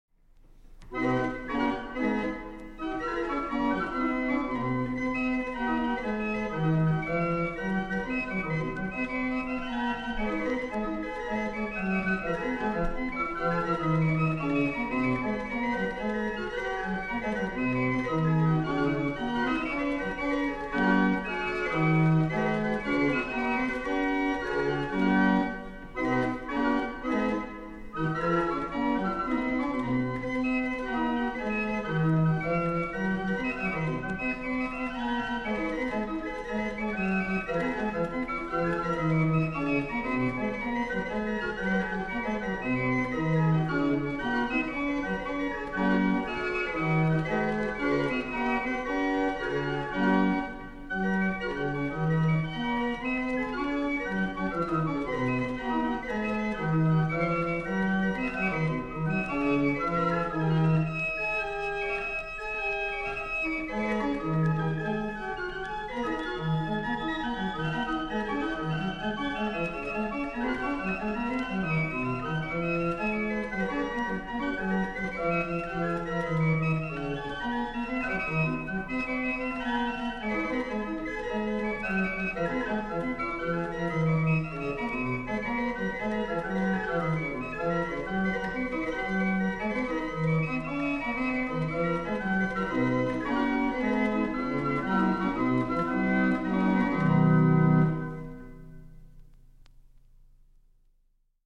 Also, I've been looking at a performance of Haendel, as preserved in a historic barrel organ: